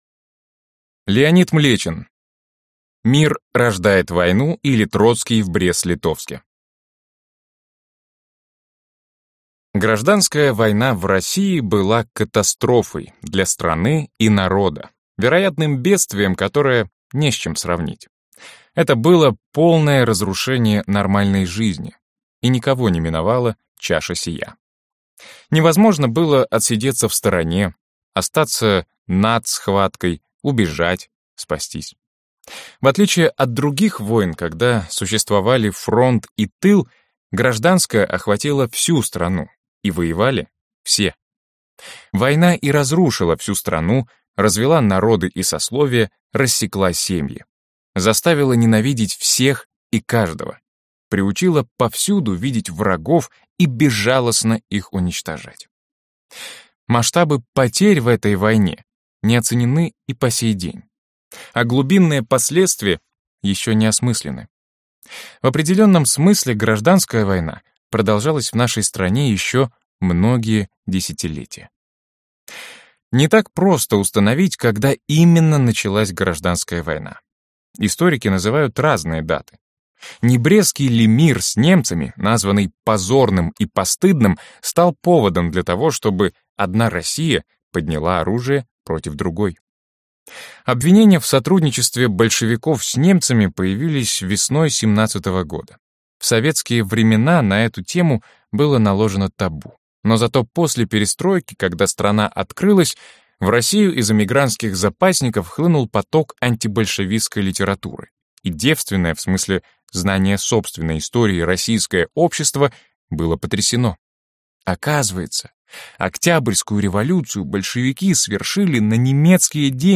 Аудиокнига Начало гражданской войны | Библиотека аудиокниг